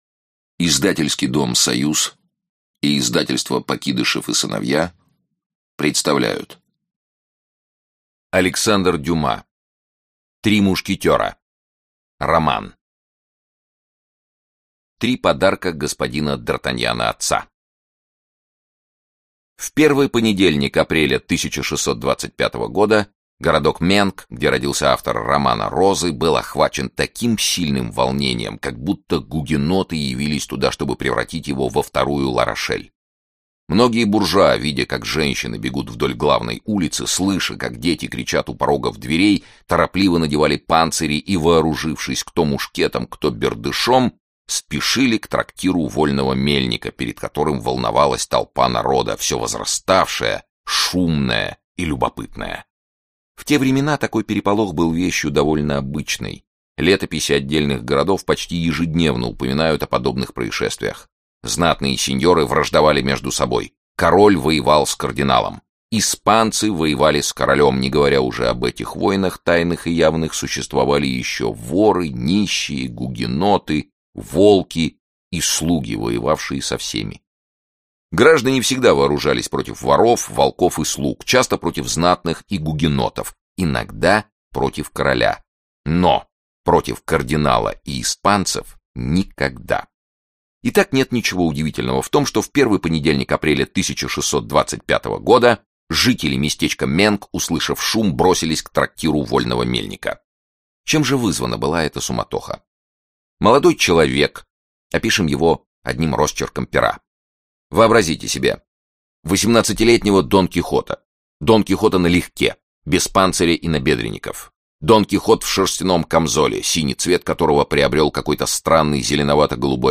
Аудиокнига Три мушкетера | Библиотека аудиокниг